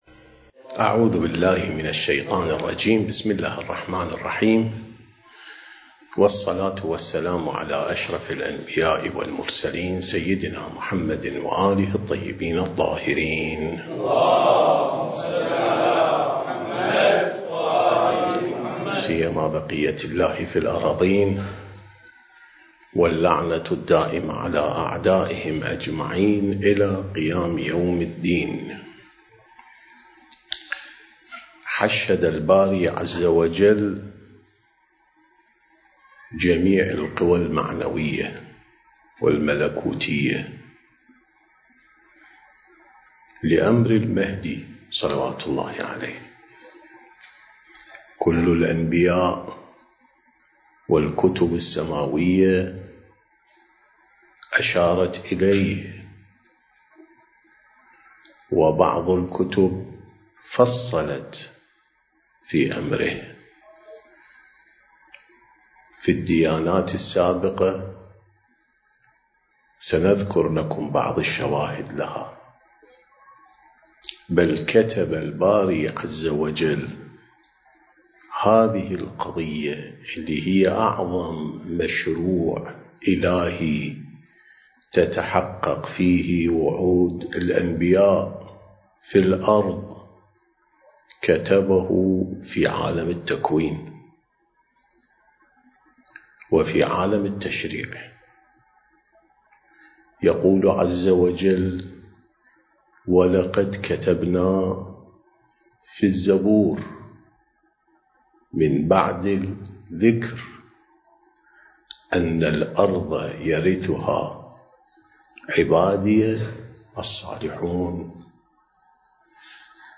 سلسلة محاضرات: الإعداد الربّاني للغيبة والظهور (2)